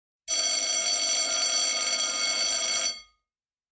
Commercial Audio are able to design and supply audio system components which can replace or work alongside your existing communication systems to provide site wide initiation of a lockdown in response to a threat as well as voice announcements to confirm the status of the emergency to all occupants.
Class Change Message
CLASS CHANGE.mp3